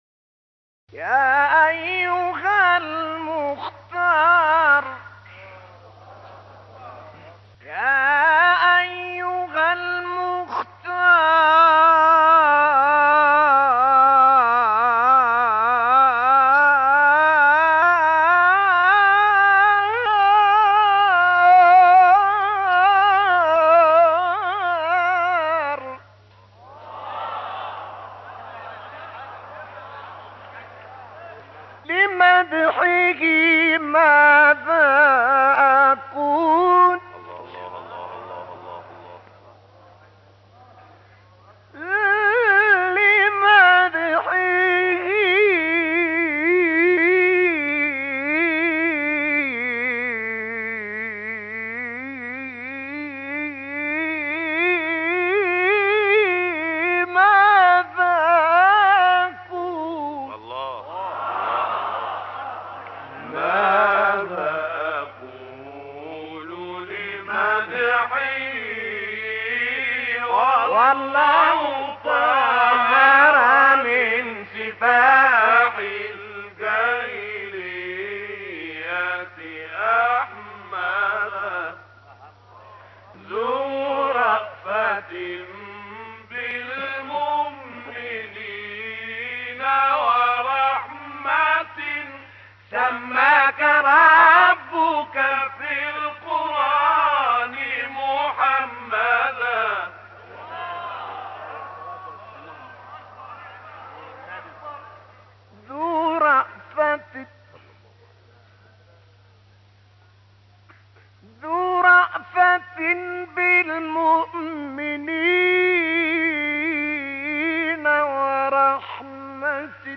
ابتهال فوق العاده زیبا از طه الفشنی
استاد «طه موسی حسن» مشهور به «طه الفشنی» از مبتهلان و قاریان قرآن كریم مصر در سال ۱۹۰۰ میلادی مطابق با ۱۲۷۹ هجری شمسی در شهر «فشن» از توابع استان «بنی سویف» دیده به جهان گشود.
طه الفشنی در بسیاری از تحریرهای خود در پایان یك فراز از آیه، از تحریرهای بسیار نرم و فنی استفاده می‌كند و اگر بخواهیم لحن وی در تلاوت قرآن را نیز بررسی كنیم، از برخی تركیب‌های لحنی ناب و مختص به خود در تلاوت قرآن استفاده می‌كند.